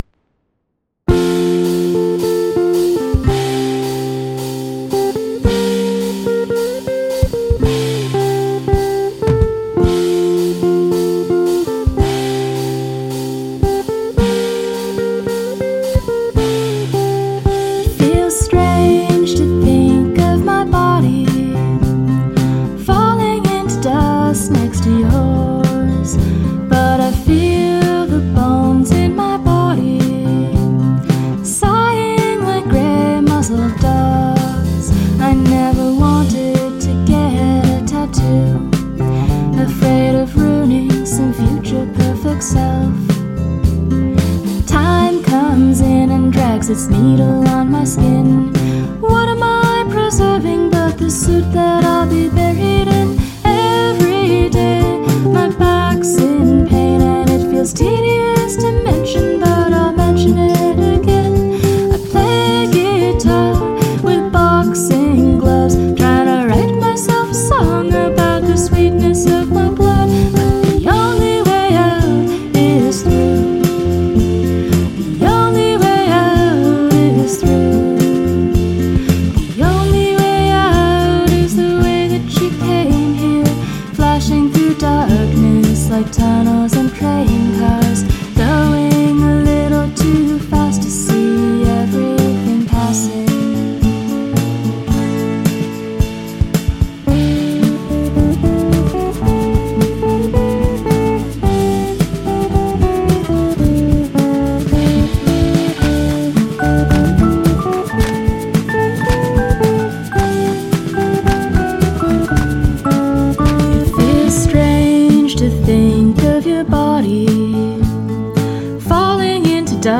Downward Modulation
I love the roving bassline.